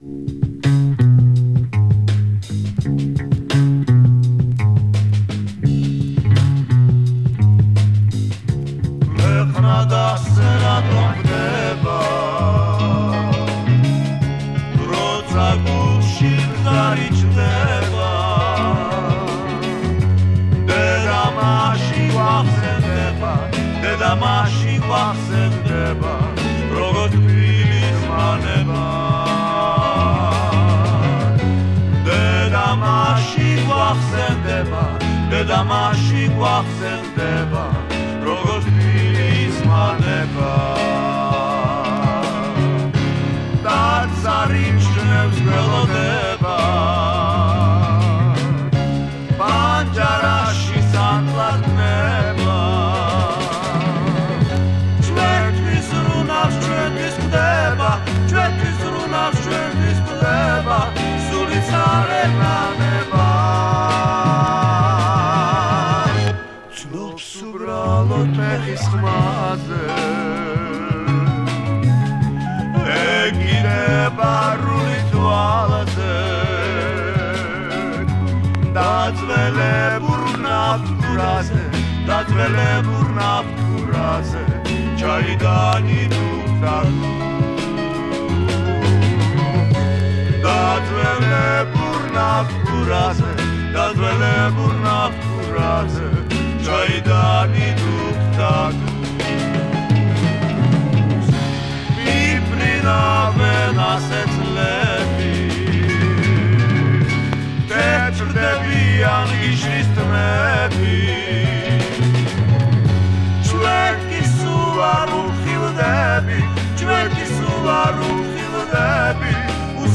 Russian Soviet Druggy Psych Break album 70s